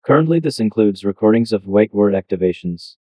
TTS cache again.